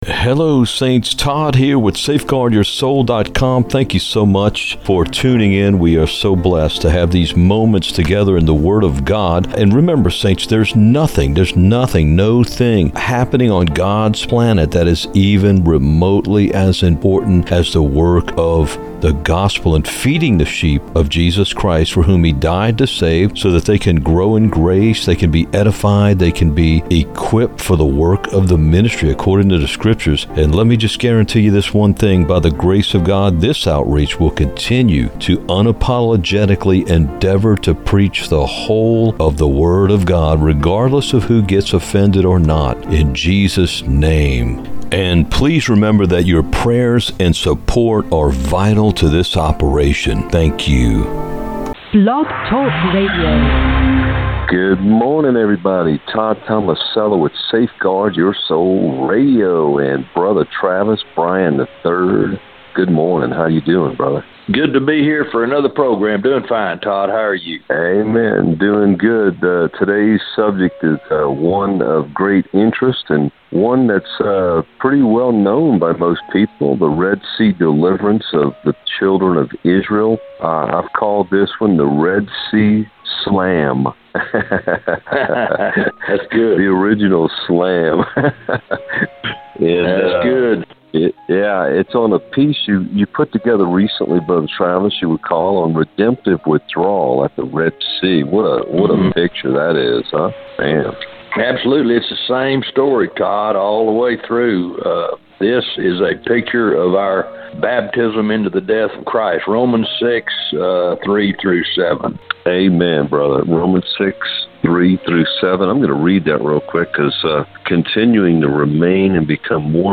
Red Sea Slam with Judge Travis Bryan III [radio] - SafeGuardYourSoul